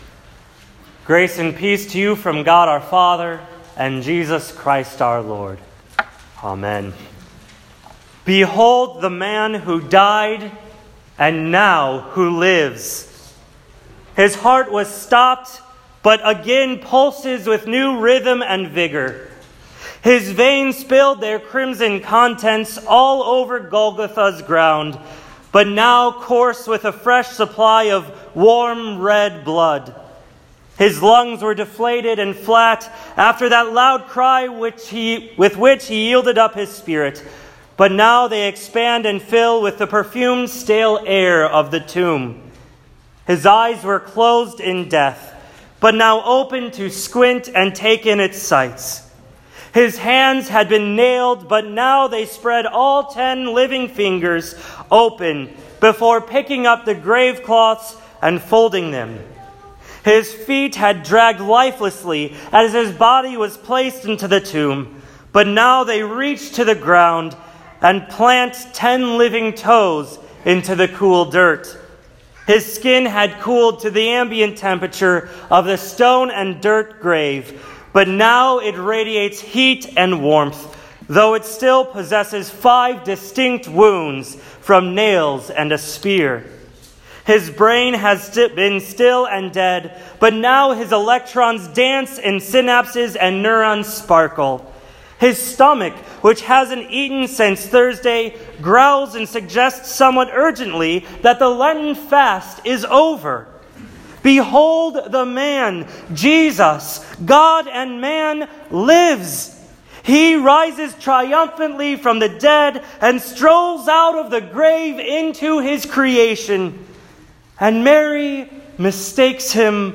Easter Day Service